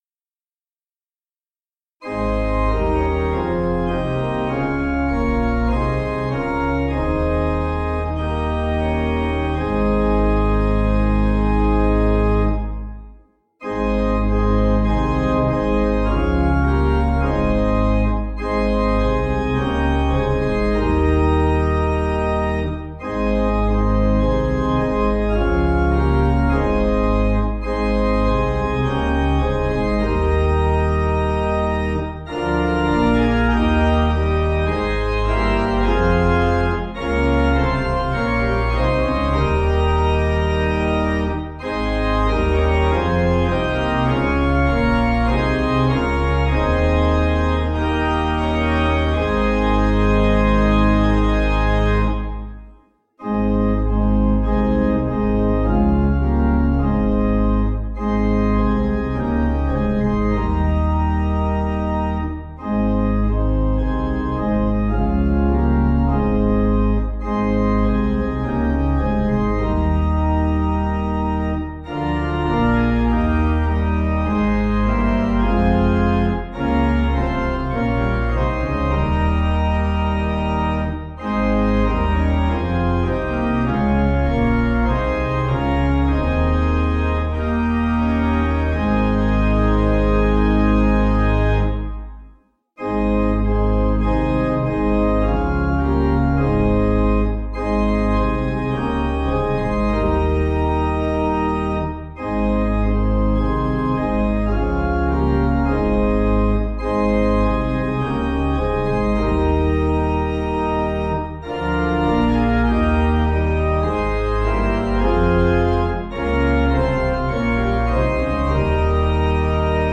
Organ
(CM)   4/Ab 473.9kb